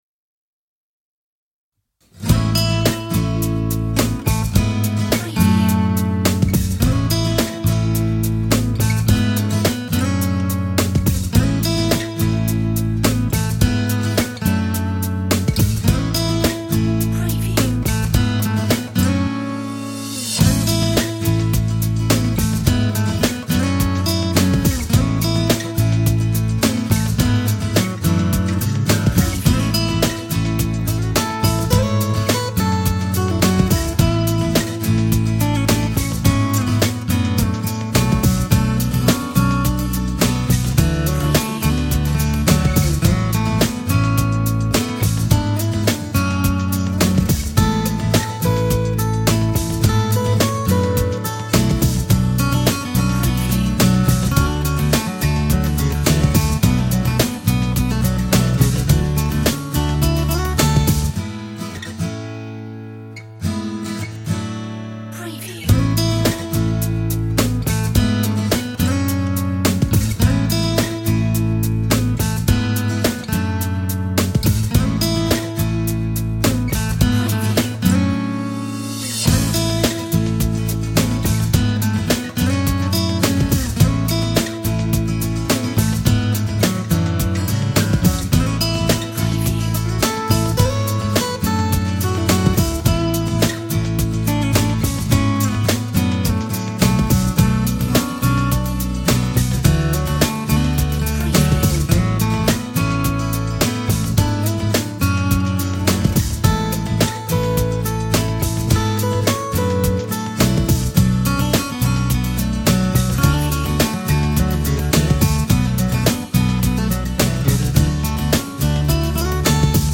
Upbeat acoustic track